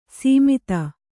♪ sīmita